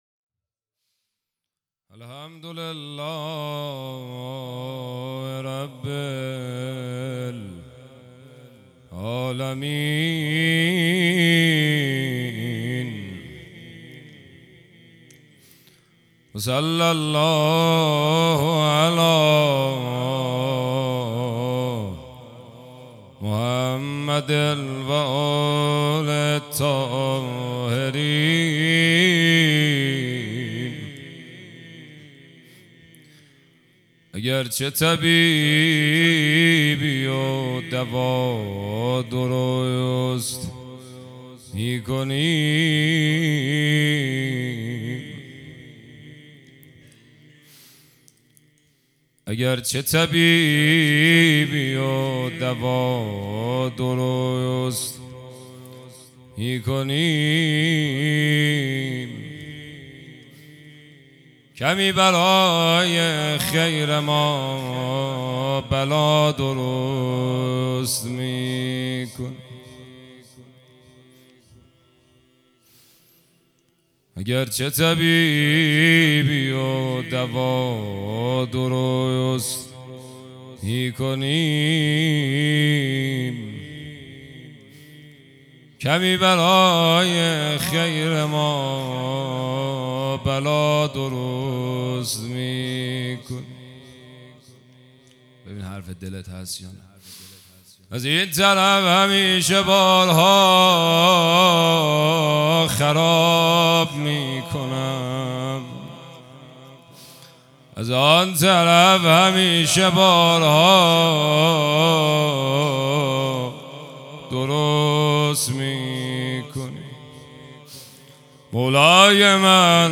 روضه _شهادت حضرت رباب (س)1403